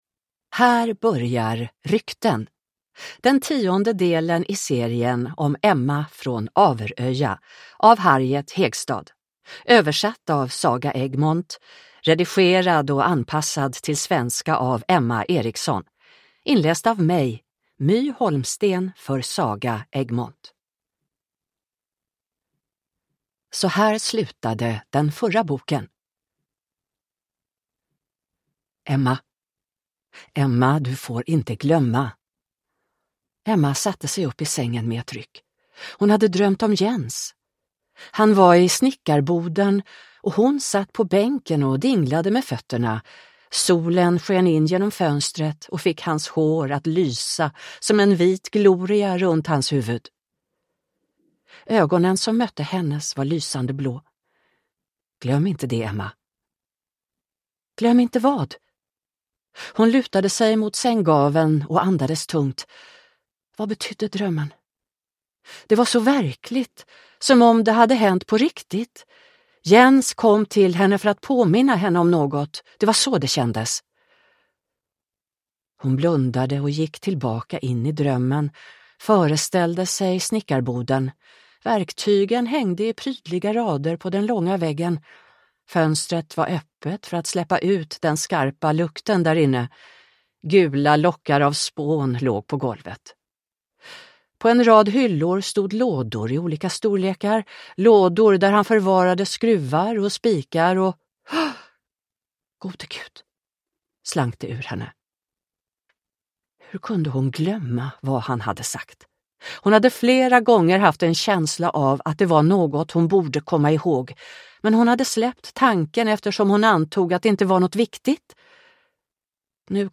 Rykten – Ljudbok